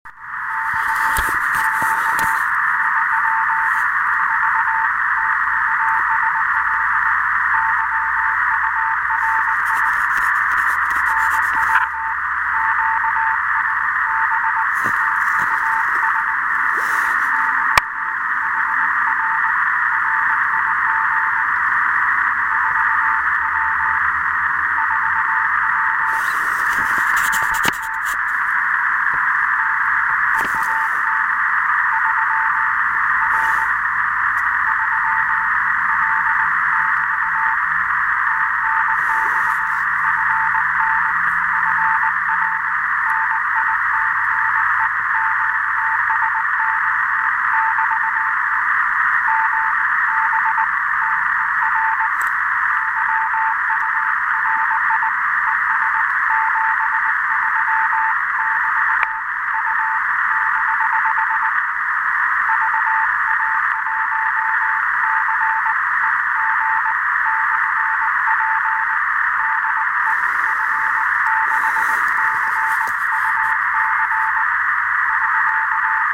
Поработал QRPP/p в лесах-полях на трансвертере, 400 мВатт, Delta, 14 мГц.